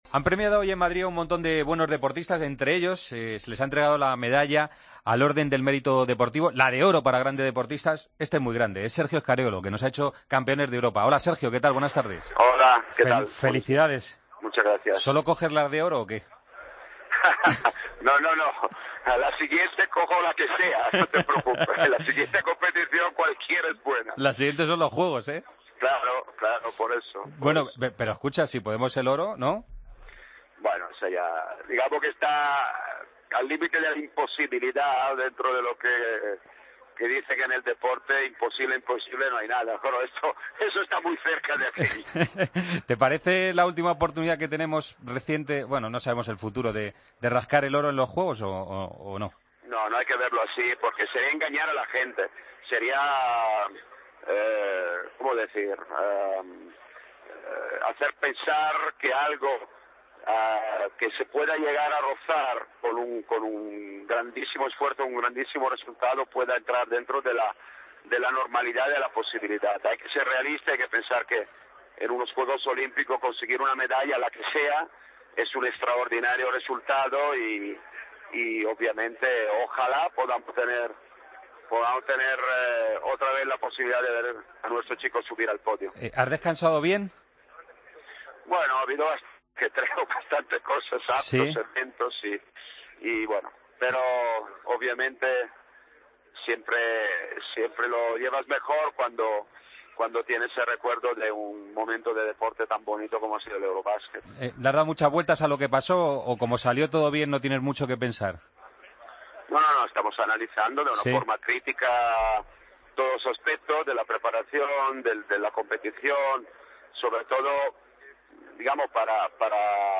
El seleccionador español de baloncesto habla en Deportes COPE tras recibir la Real Orden del Mérito Deportivo. Scariolo reconoce que empezarán a preparar los Juegos antes que el pasado Eurobasket y sabe de la dificultad de vencer a Estados Unidos.